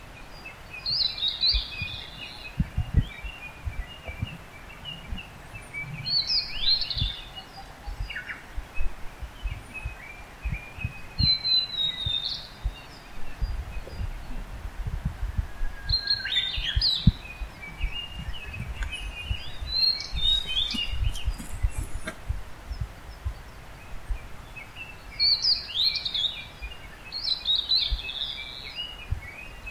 ohruri.mp3